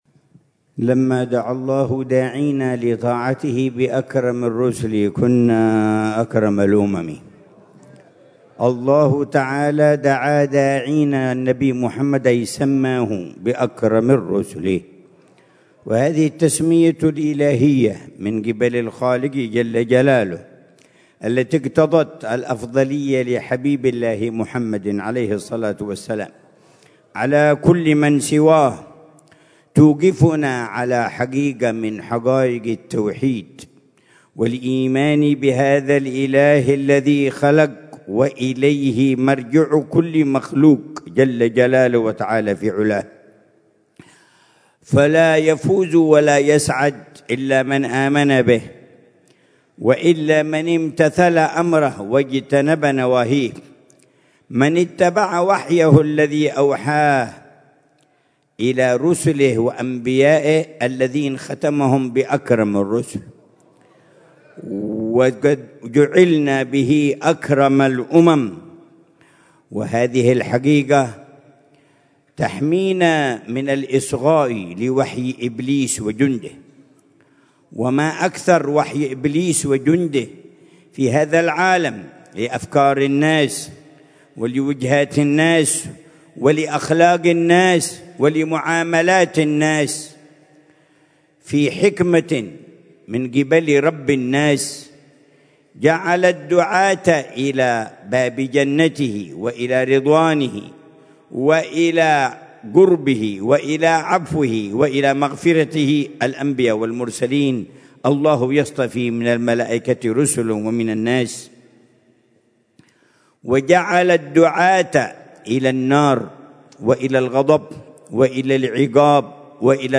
محاضرة
في دار المصطفى